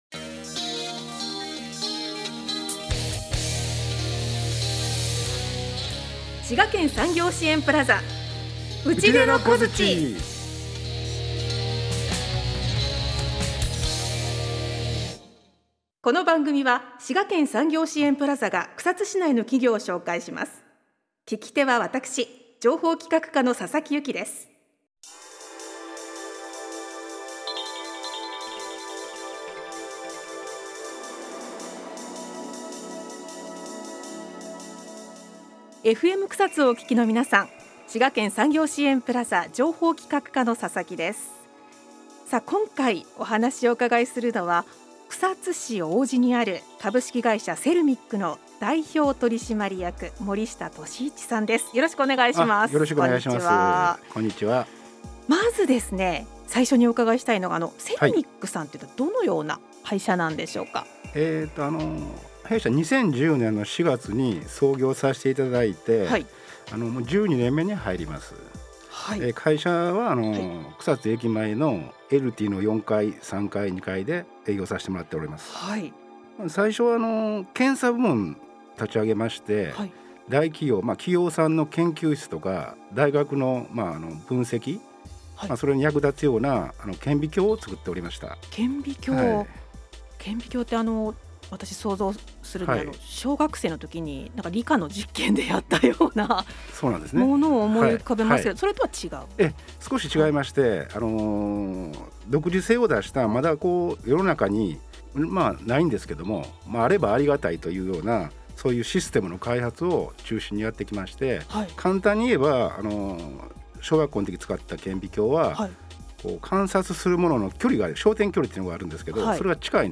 公益財団法人滋賀県産業支援プラザの協力で、草津市内の企業などを紹介する番組がスタートしました。 放送は、第3水曜日の11時からの30分番組。